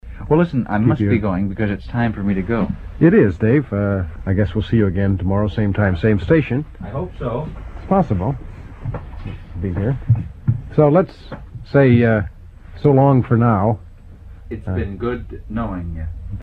live on CHUM-FM (edit)